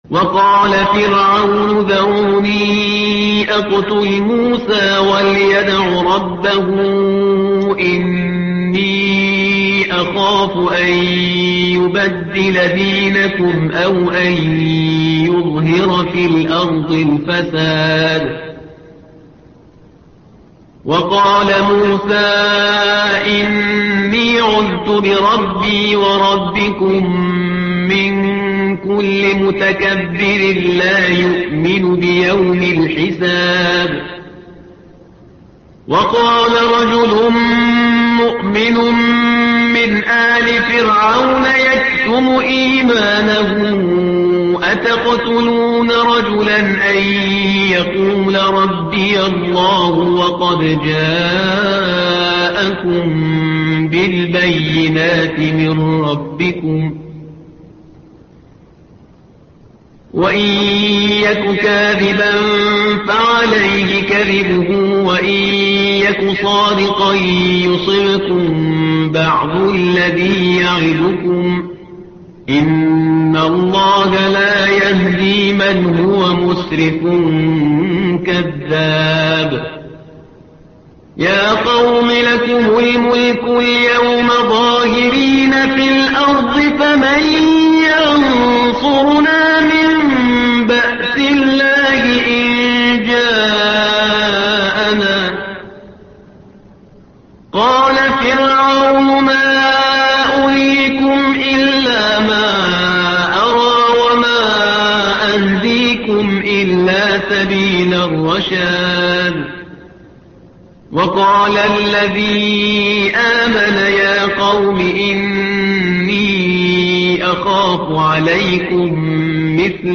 الصفحة رقم 470 / القارئ